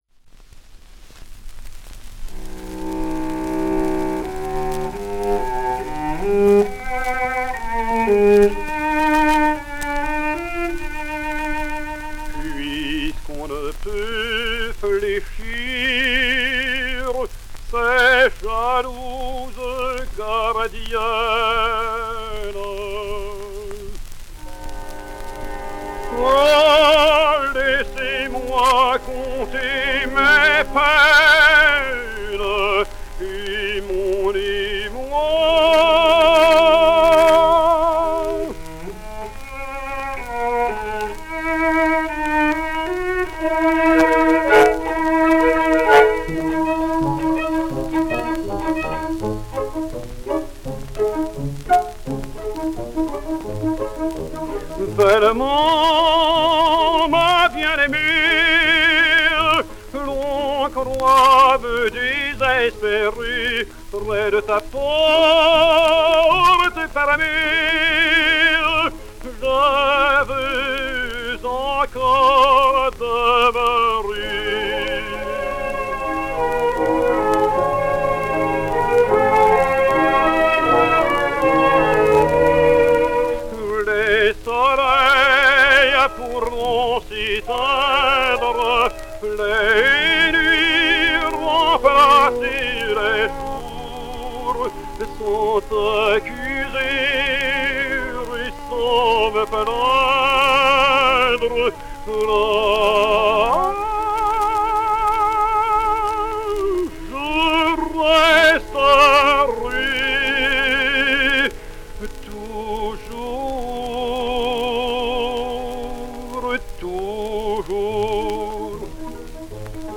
A native of Algiers, he was primarily an operetta tenor, and only occasionally appeared in opera.